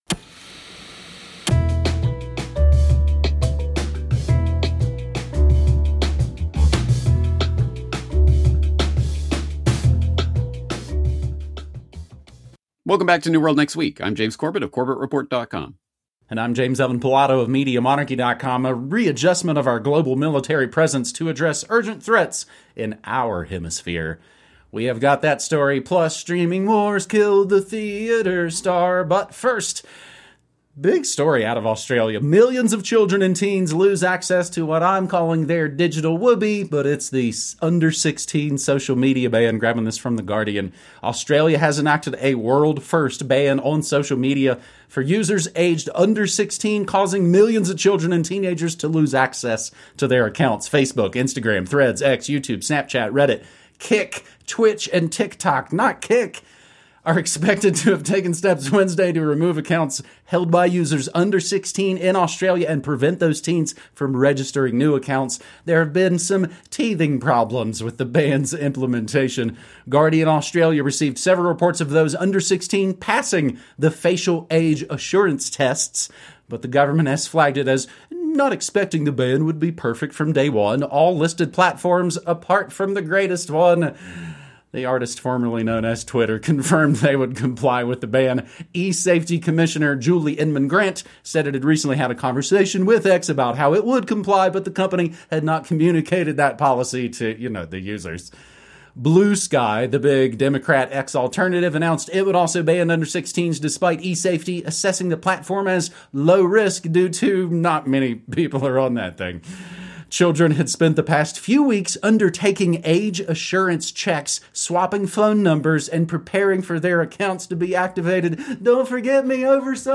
Interview 1992 – Parents Teaching Children How to Break Stupid Laws! (NWNW #612)